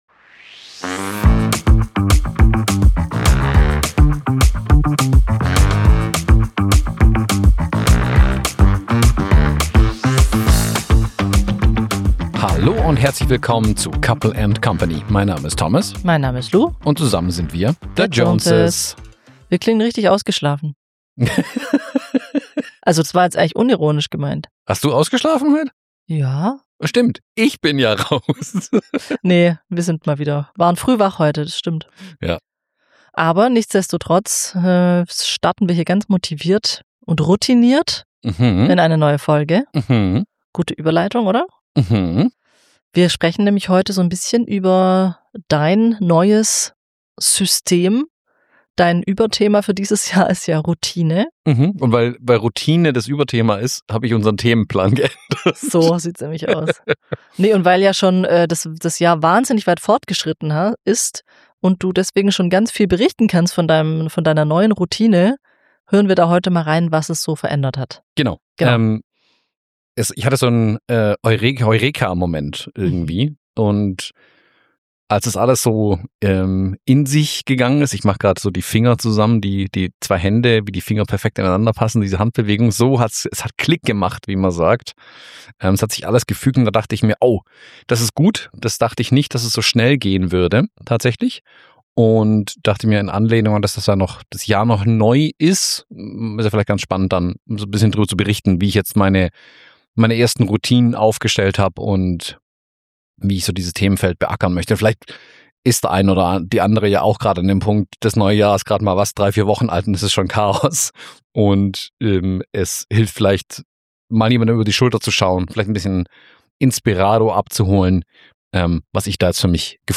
Wir klingen ausgeschlafen, sind es aber nur so halb - und sprechen trotzdem erstaunlich klar über ein Thema, das uns gerade sehr beschäftigt: Routinen.